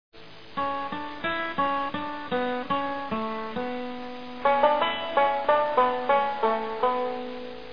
frightening sound.